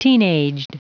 Prononciation du mot teenaged en anglais (fichier audio)
Prononciation du mot : teenaged